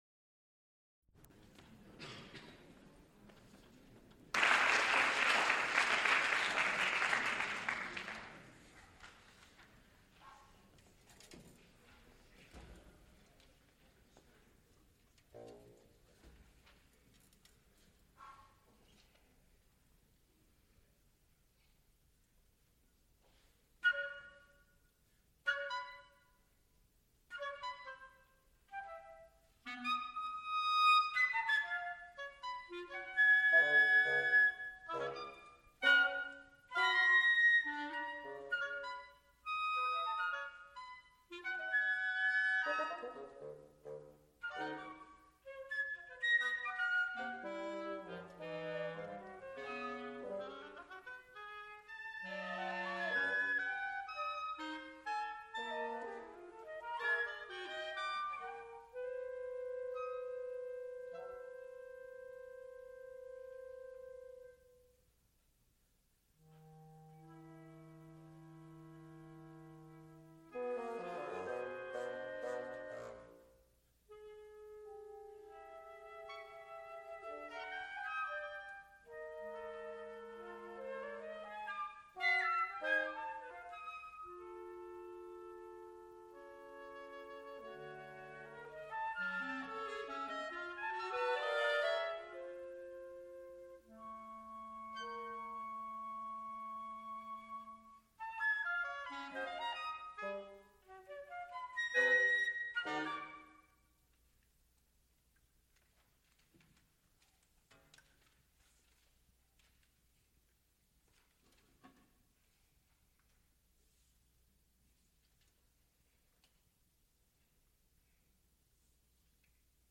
Cantatas, Sacred
Recorded live April 11, 1954, Heinz Chapel, University of Pittsburgh.
Sound, musical performances
analog, half track, stereo